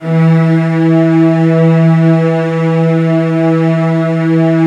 CELLOS F#3-L.wav